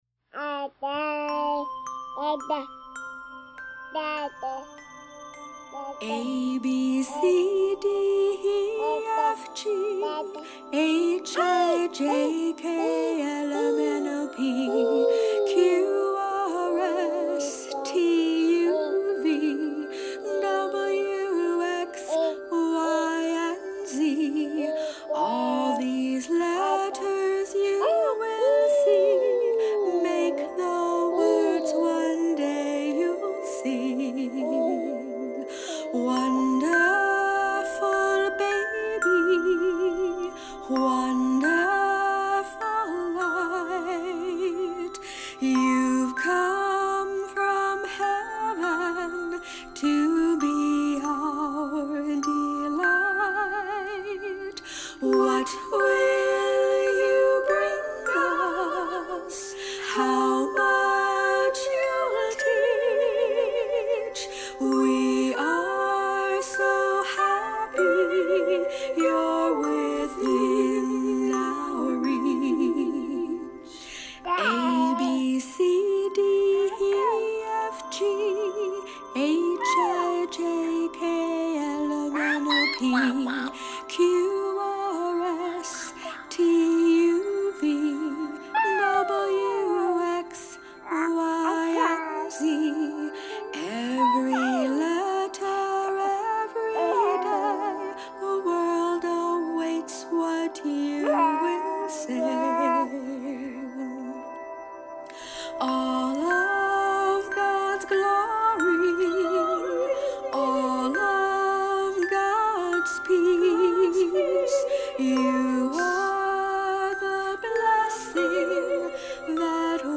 Vocals
Glockenspeil
Soundscape